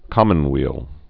(kŏmən-wēl)